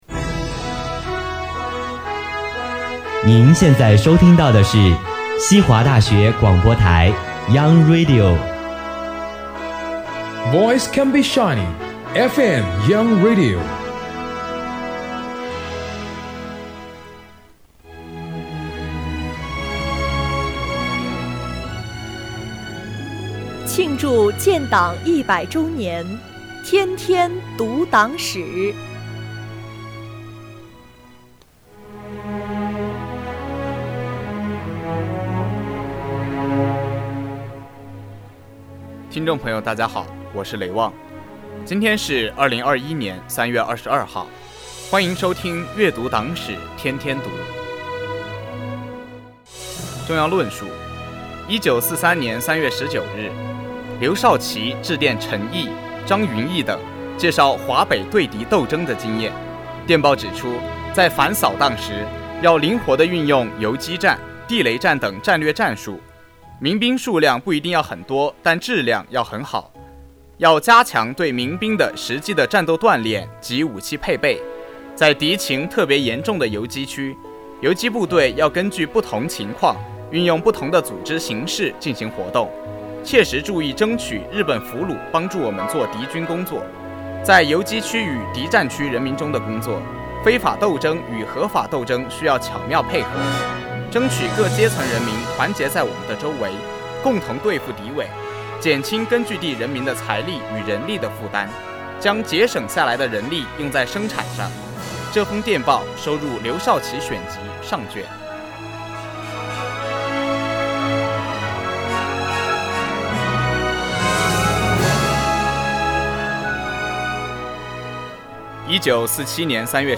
西华学子悦读党史